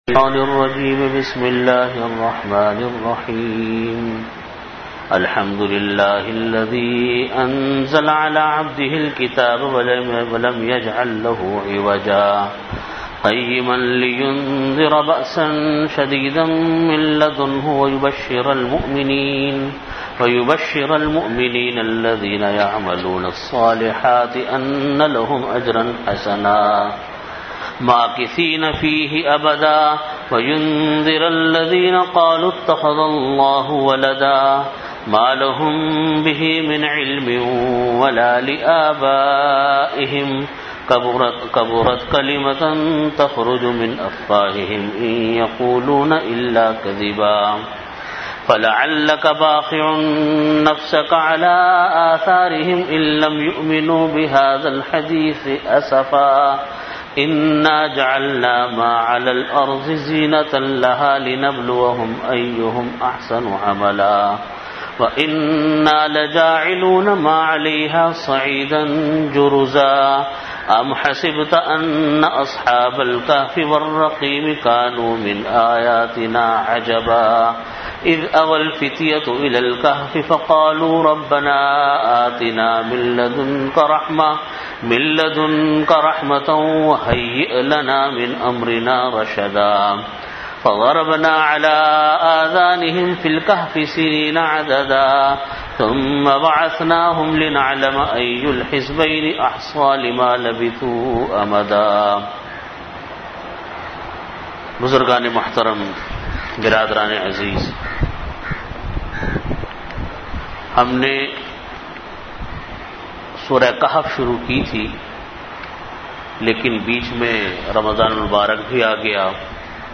Category: Bayanat
48min Time: After Asar Prayer Venue: Jamia Masjid Bait-ul-Mukkaram, Karachi